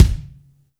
INSKICK15 -L.wav